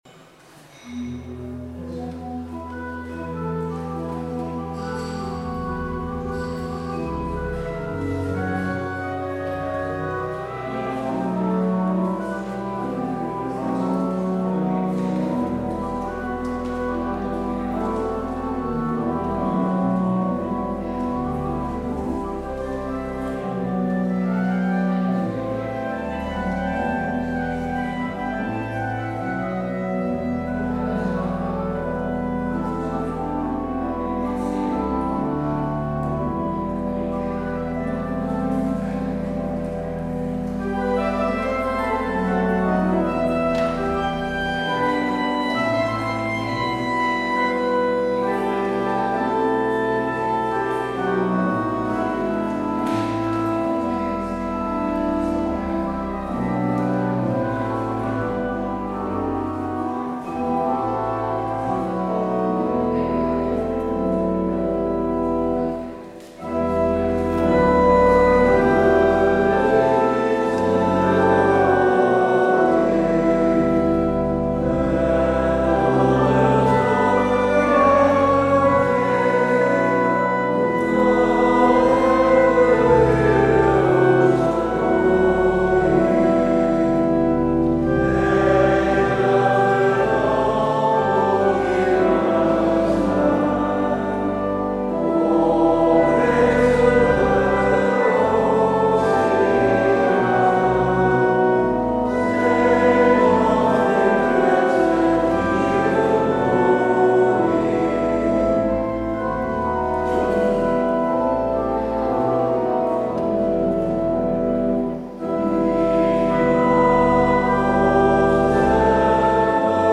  Luister deze kerkdienst hier terug
Als openingslied wordt gezongen: Wek mijn zachtheid weer ( LvK 925, 3 x zingen).